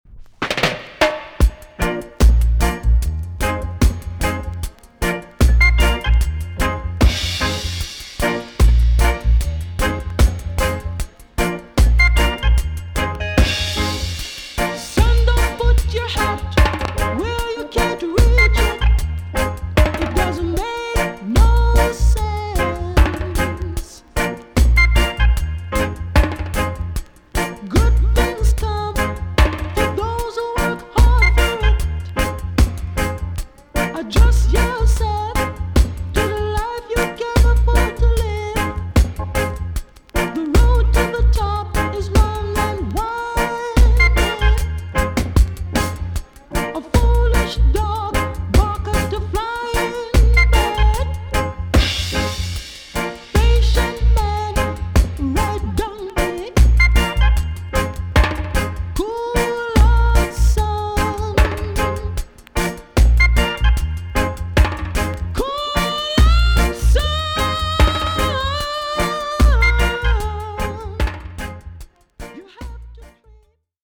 TOP >REGGAE & ROOTS
EX 音はキレイです。